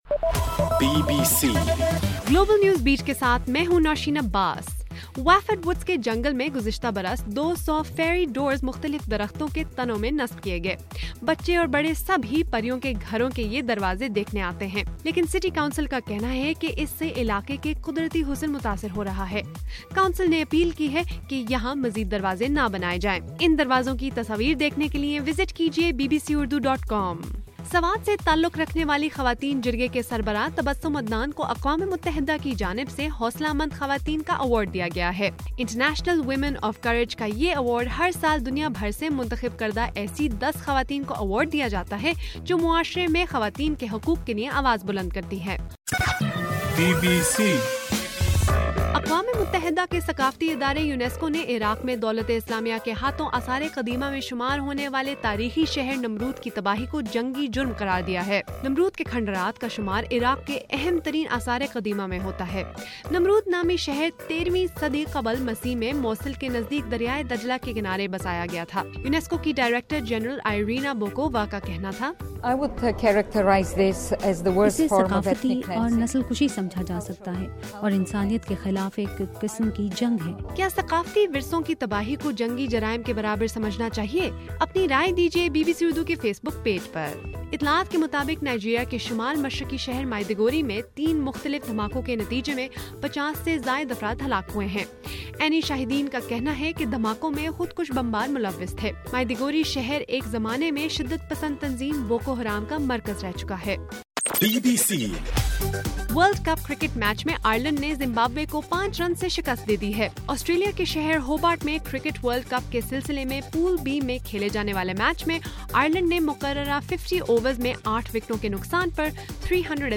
مارچ 8: صبح 1 بجے کا گلوبل نیوز بیٹ بُلیٹن